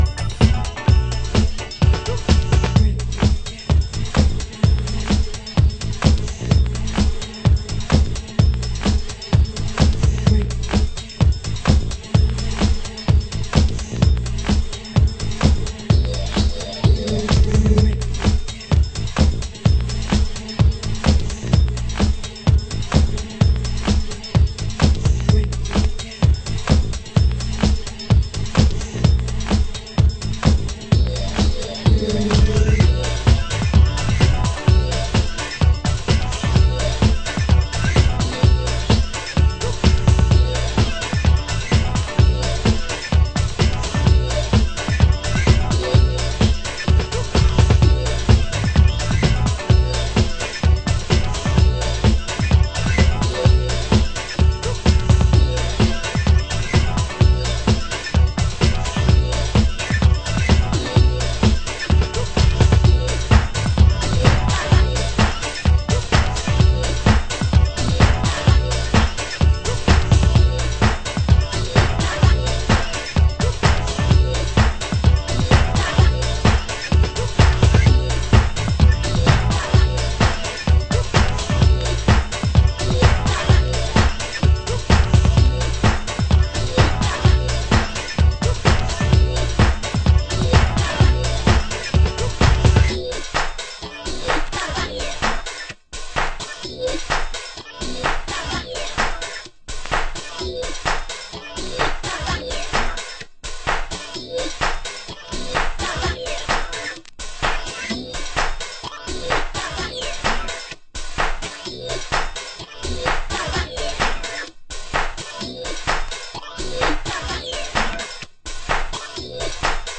盤質：チリパチノイズ有　　　ジャケ：左上部折れ有